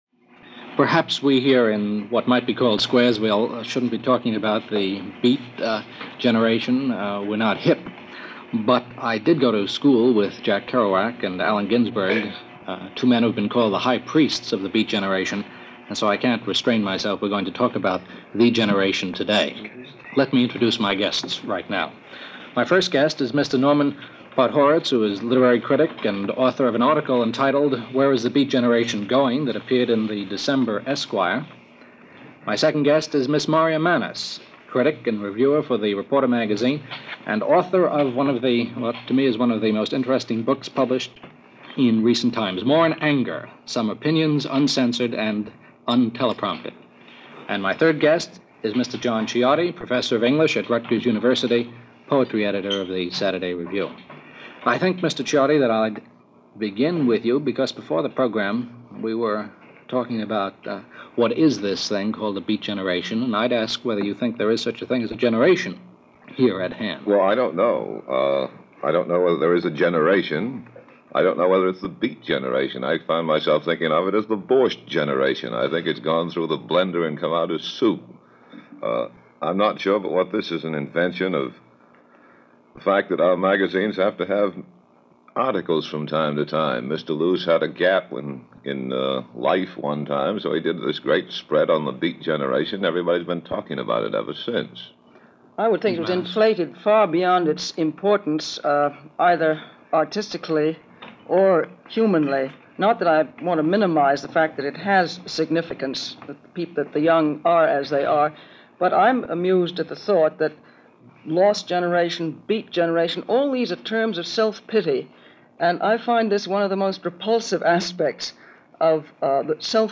In 1958, mainstream media couldn’t wait to pronounce the movement dead and buried – and as is evidenced by this panel discussion, which includes literary notables Marya Mannes, Norman Podhoretz and John Ciardi.